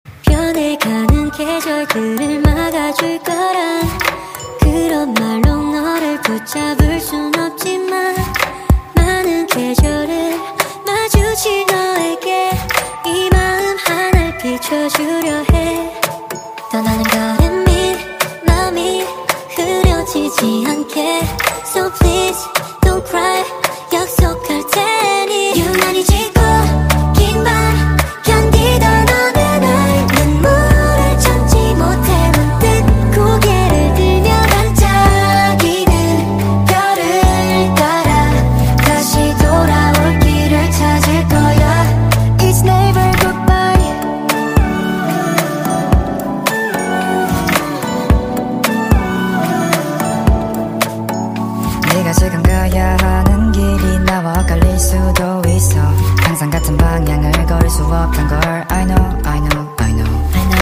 -One of the symptoms is that knocking sound can be heard when turning left and right